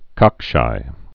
(kŏkshī)